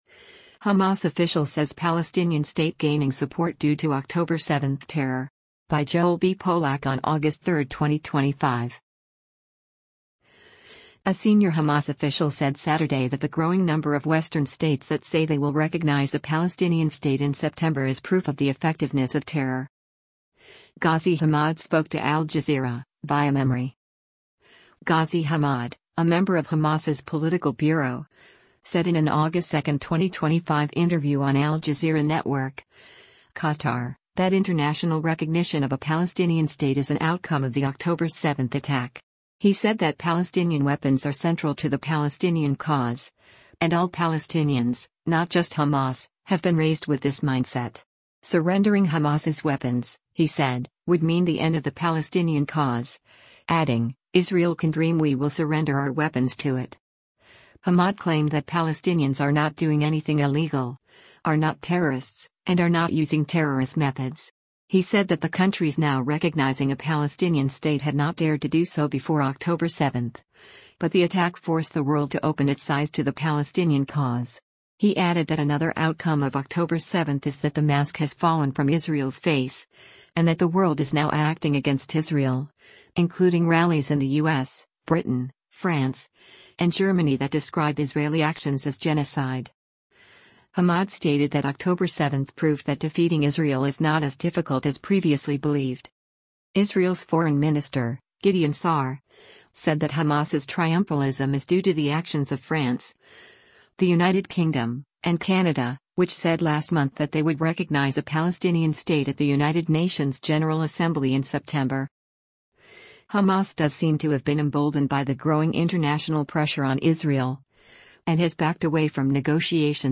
Ghazi Hamad spoke to Al Jazeera (via MEMRI):
Ghazi Hamad, a member of Hamas’s political bureau, said in an August 2, 2025 interview on Al-Jazeera Network (Qatar) that international recognition of a Palestinian state is an outcome of the October 7 attack.